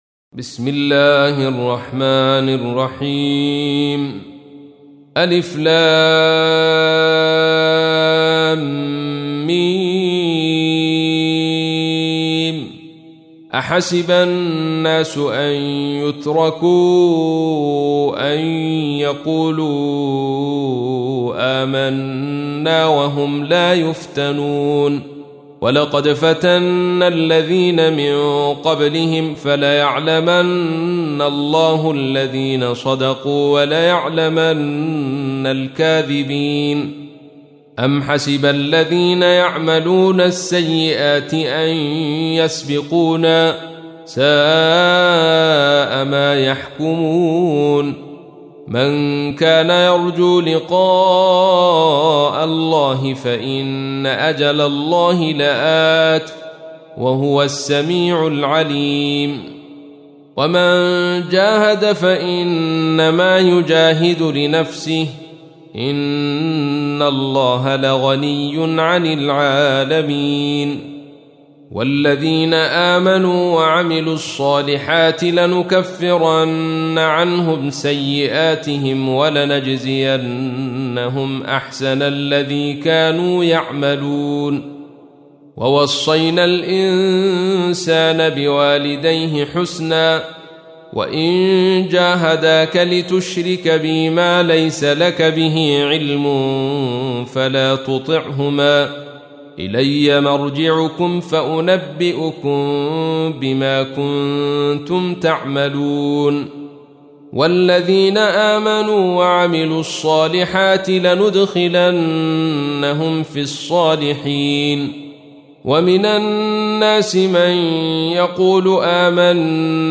سورة العنكبوت | القارئ عبدالرشيد صوفي